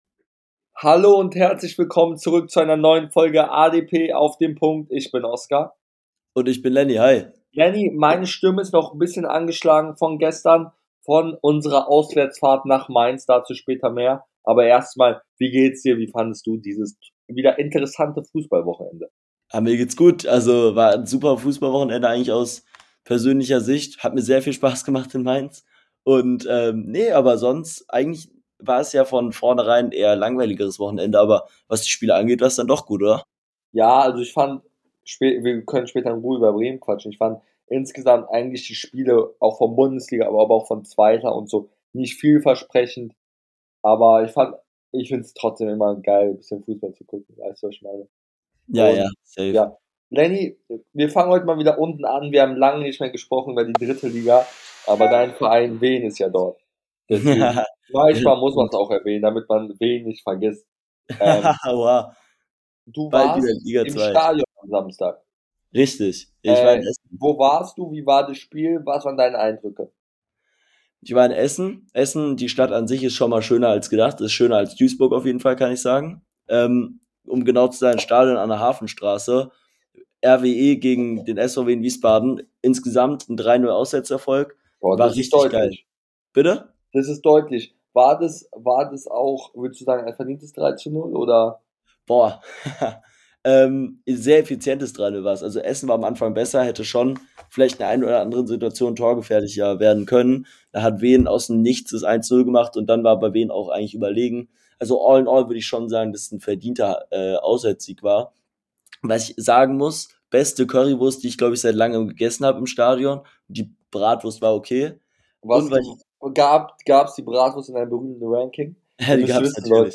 In der heutigen Folge reden die beiden Hosts über Kohfeldts Debüt ,Köhns Debüttreffer gegen Mainz , Bayerns Schützenfest und vieles mehr